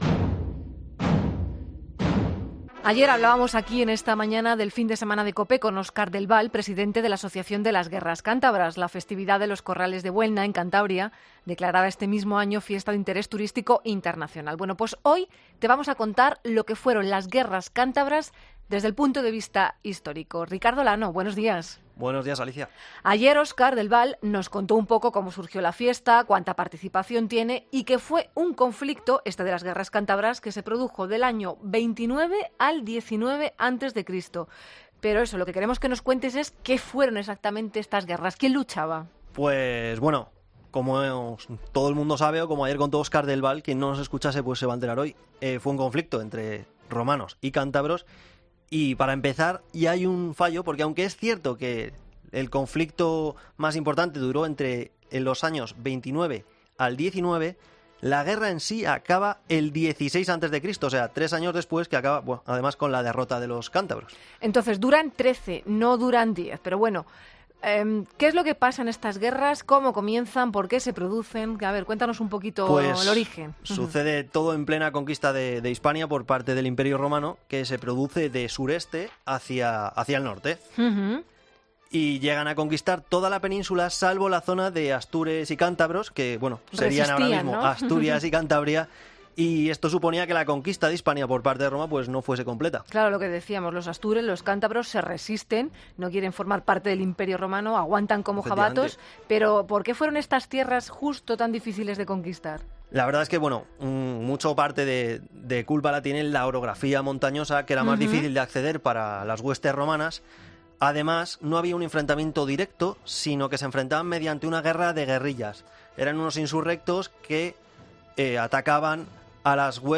Para conocer cómo se viven estas fiestas desde dentro hemos hablado con un cántabro y una romana.